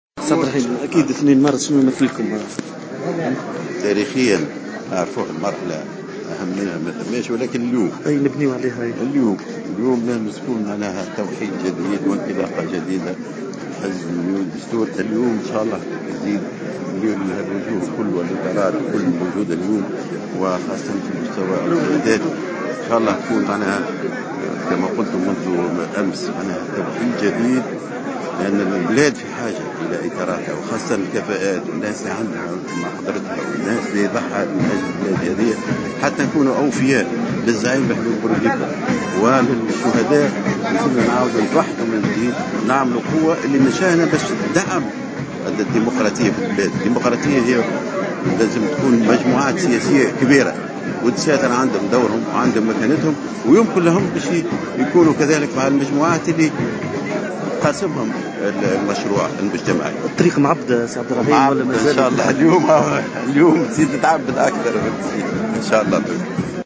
L’ex-ministre Abderrahim Zouari, a déclaré ce mercredi 2 mars 2016 au micro du correspondant de Jawhara FM, que le 2 mars 1934 est la date la plus importante historiquement.